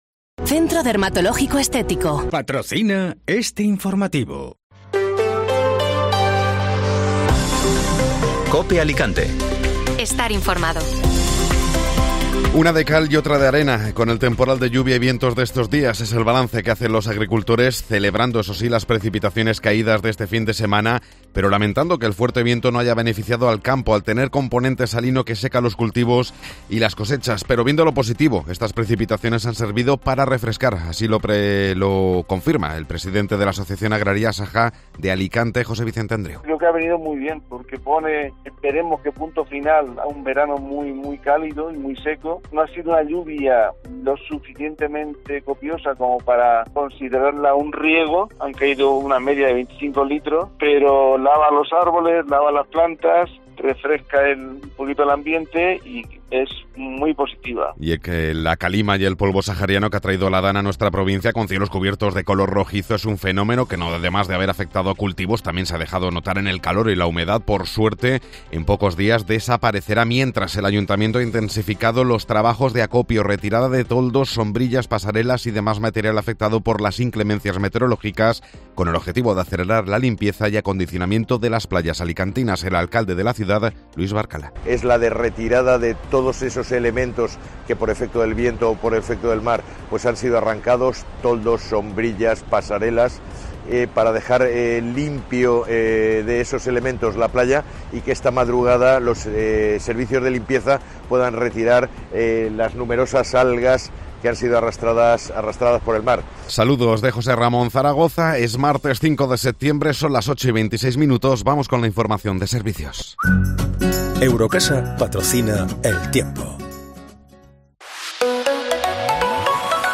Informativo Matinal (Martes 5 de Septiembre)